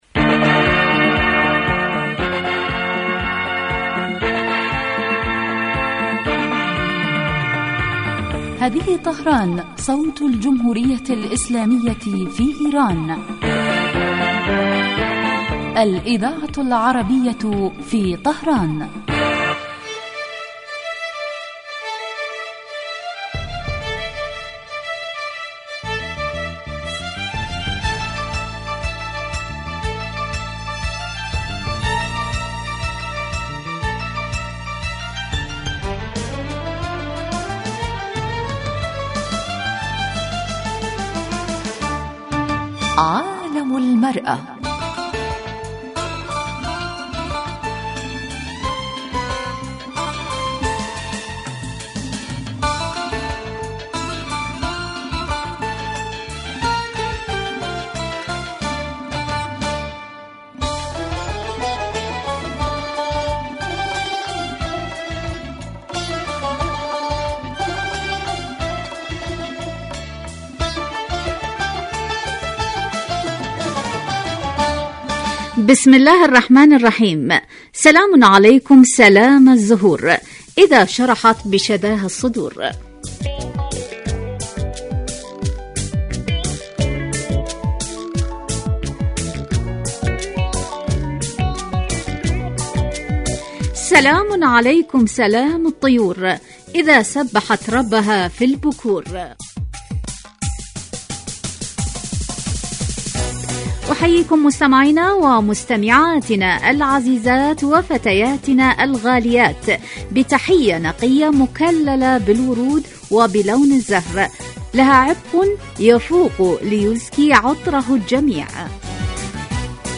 من البرامج الناجحة في دراسة قضایا المرأة فی العالمین الإسلامي و العربي ومعالجة ما لها من مشکلات و توکید دورها الفاعل في تطویر المجتمع في کل الصعد عبر وجهات نظر المتخصصین من الخبراء و أصحاب الرأي مباشرة علی الهوا